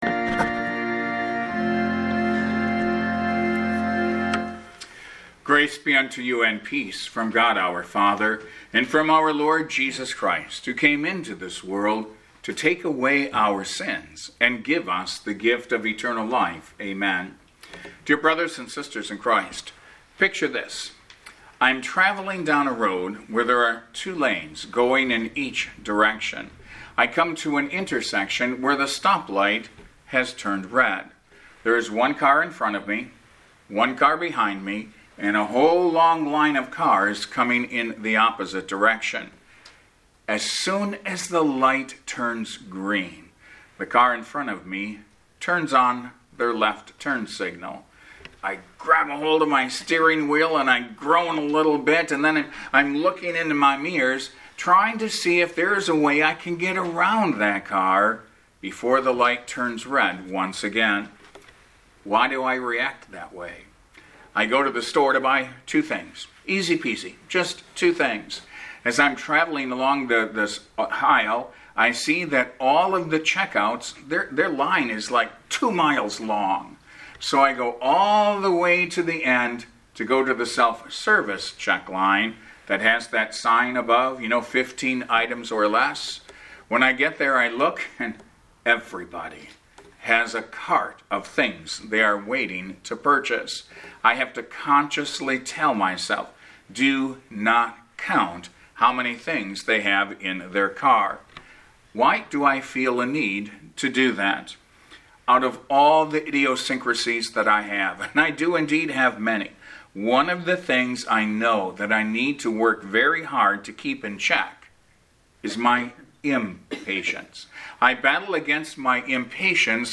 Therefore the Advent Imperative that we will study on the basis of this portion of Scripture is: Be Patient!